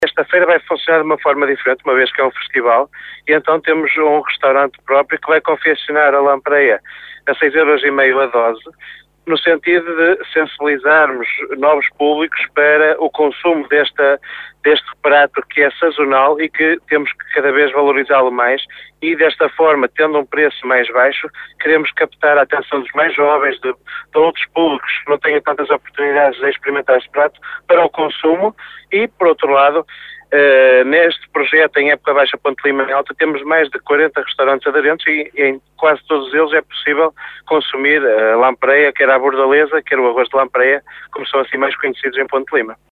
O vereador do Turismo, Paulo Sousa, apresenta a iniciativa